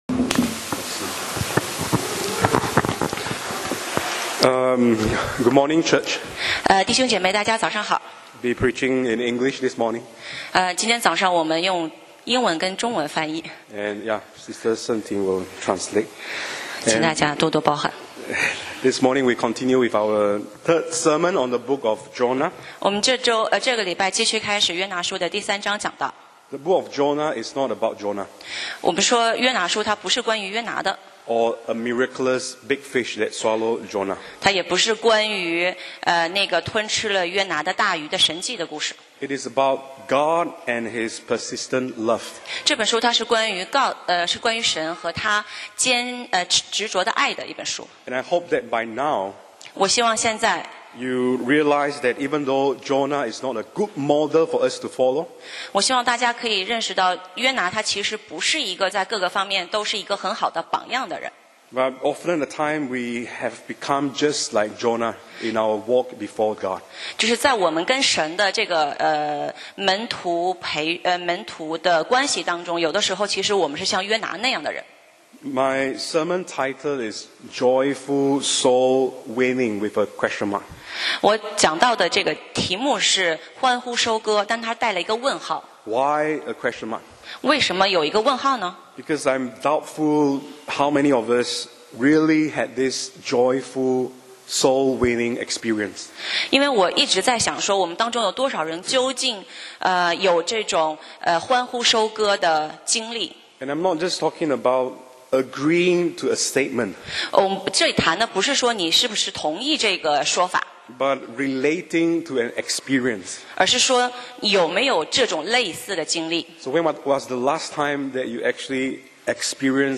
講道 Sermon 題目 Topic：欢呼收割？